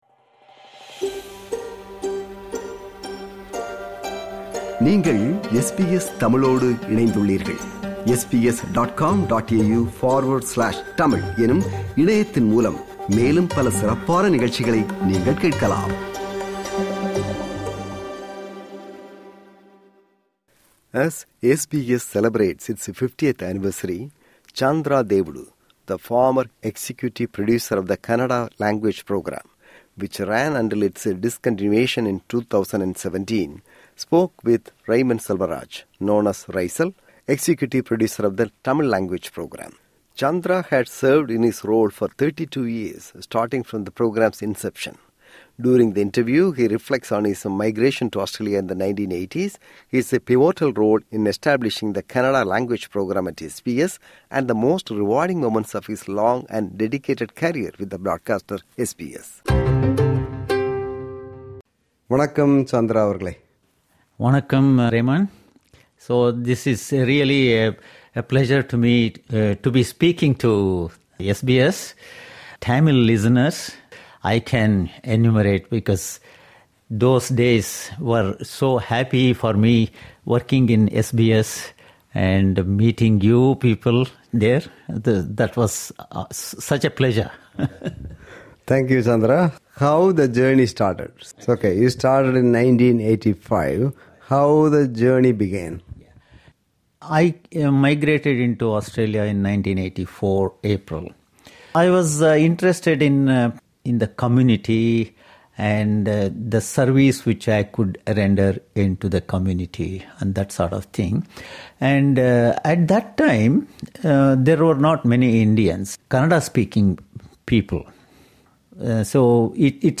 During the interview